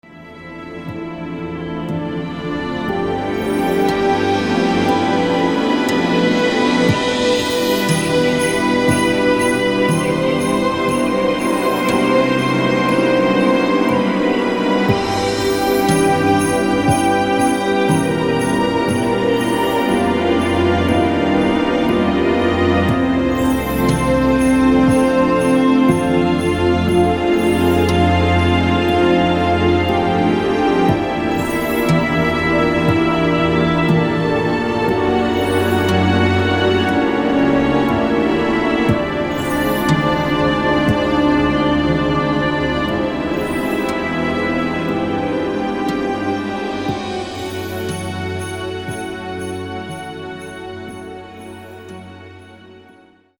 Genre: ambient.